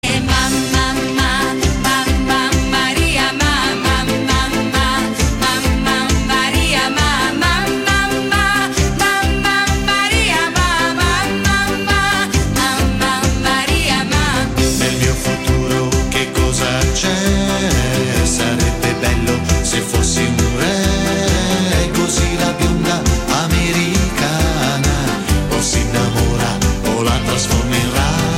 Besetzung: Blasorchester
Italo-Top-Hit
Tonart: B-Dur mit Wechsel auf C-Dur